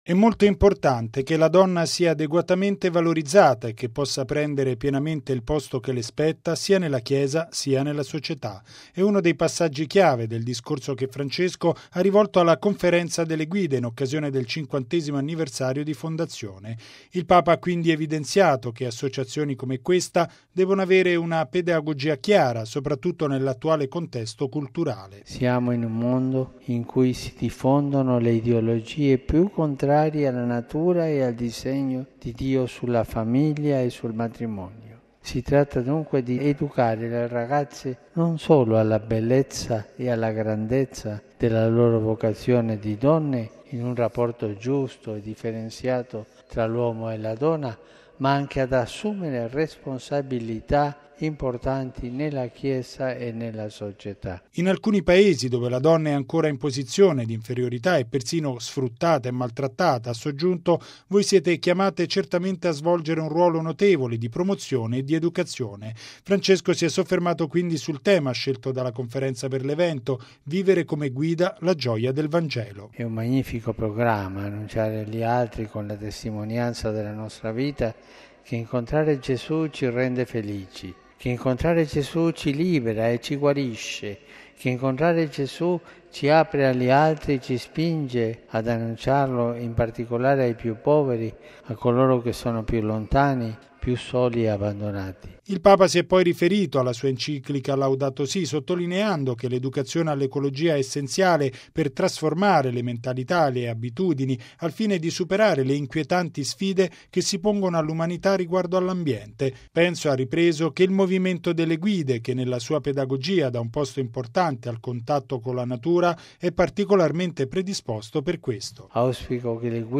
E’ l’esortazione di Papa Francesco durante l’udienza alla delegate della Conferenza internazionale cattolica delle guide. Il Pontefice è quindi tornato a denunciare la diffusione di quelle ideologie che sono contrarie alla natura e al disegno di Dio sulla famiglia e sul matrimonio.